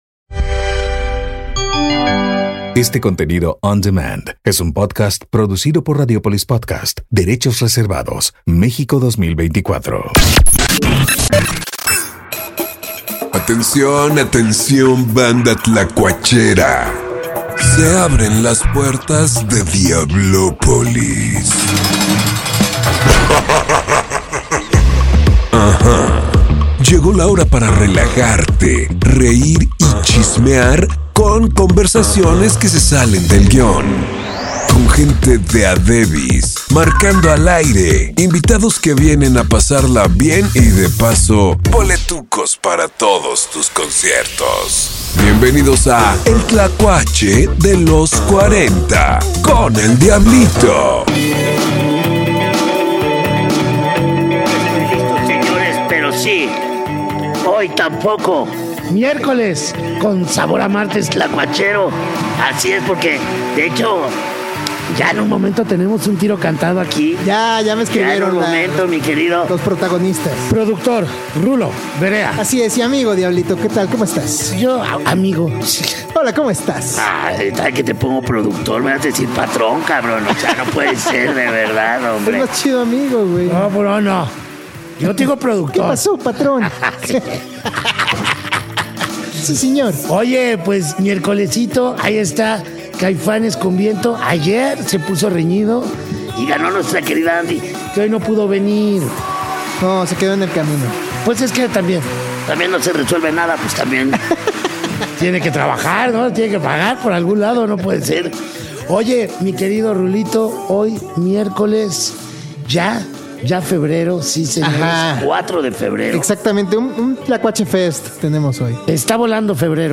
La Gusana Ciega echando palomazo en vivo